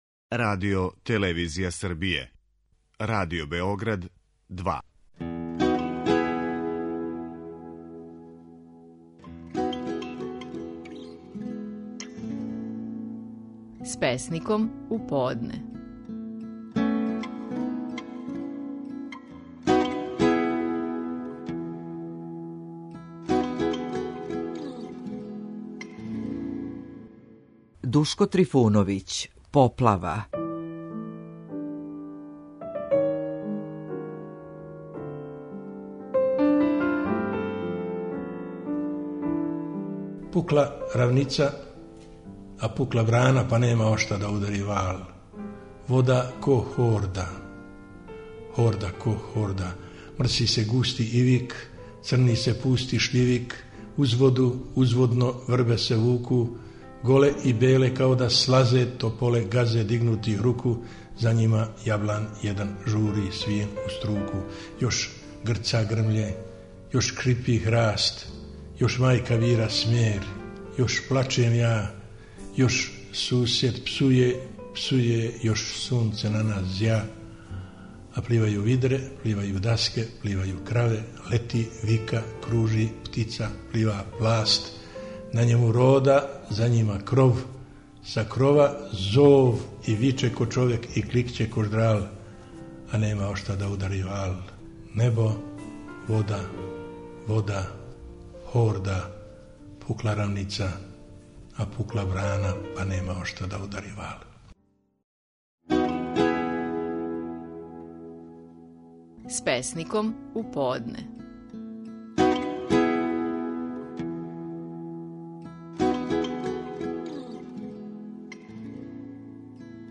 Стихови наших најпознатијих песника, у интерпретацији аутора.